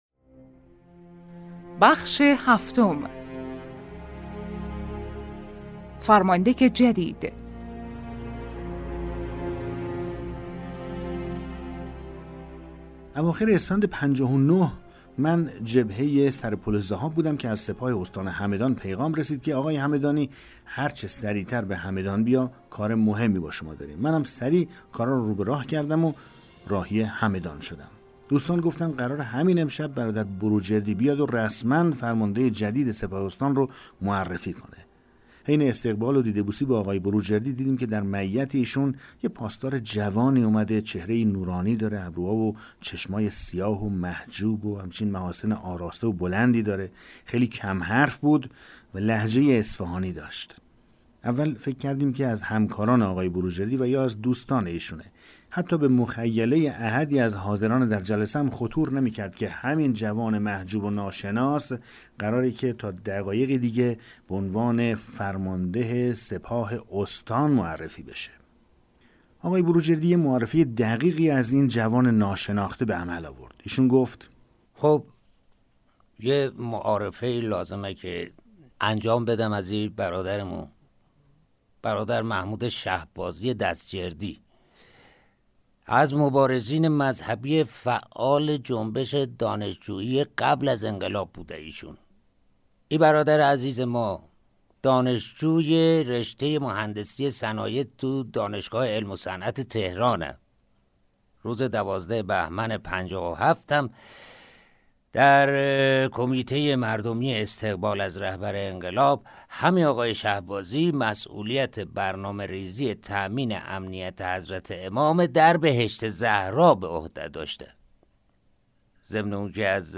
کتاب صوتی پیغام ماهی ها، سرگذشت جنگ‌های نامتقارن حاج حسین همدانی /قسمت 7